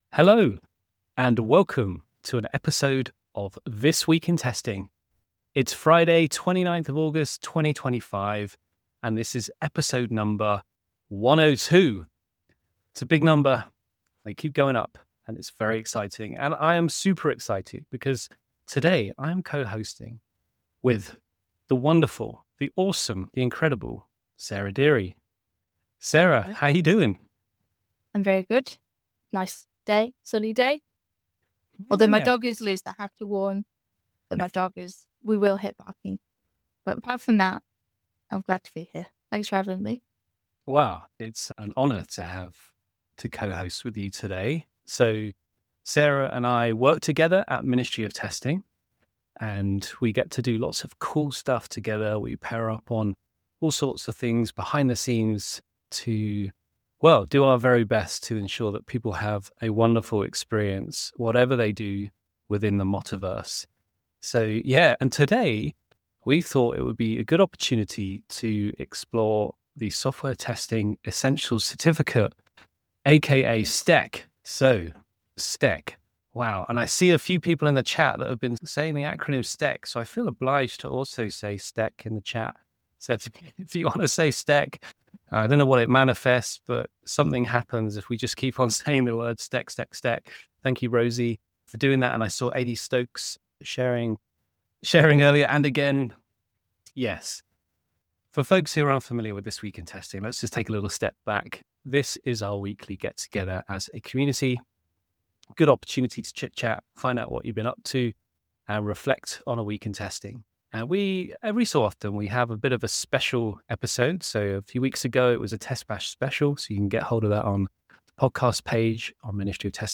🎙 Tune in for thoughtful conversations, testing news, and community insights covering everything from QA trends to quality engineering practices.